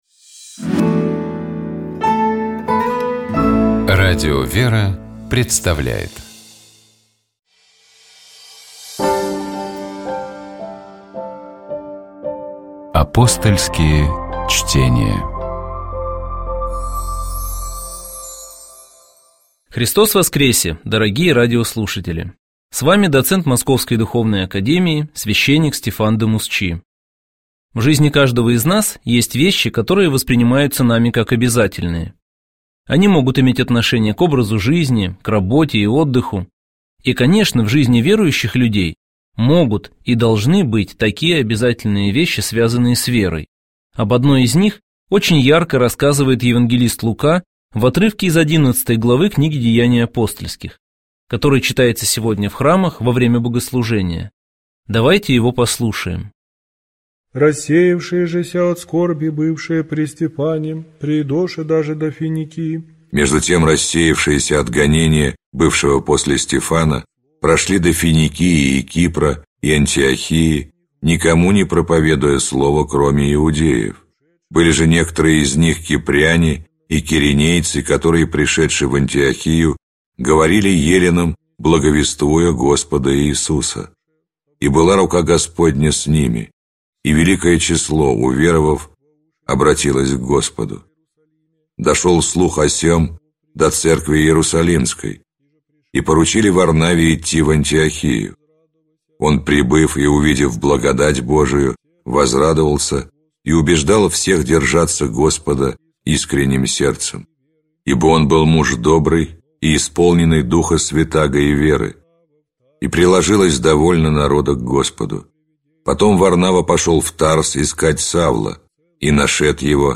Гость программы - Владимир Легойда, председатель Синодального отдела по взаимоотношениям Церкви с обществом и СМИ.